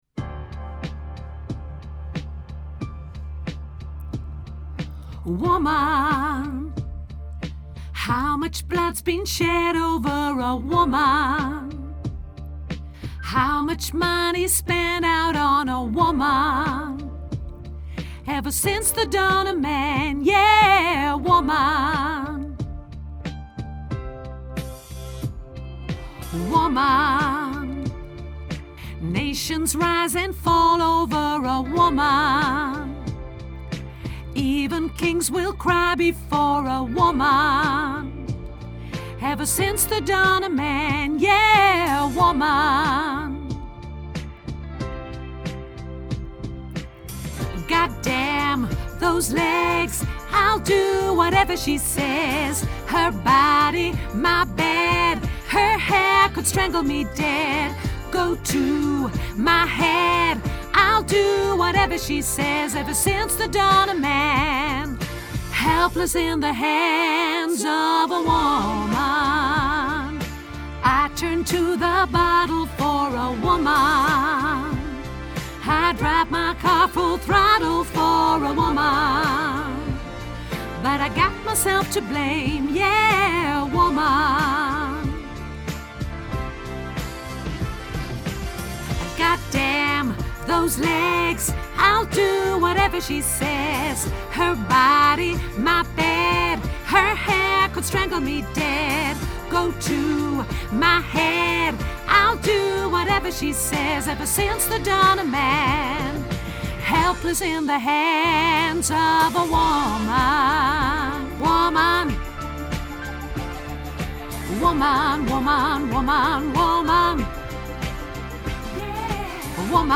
tenor
Woman Tenor Grote Koor Mp 3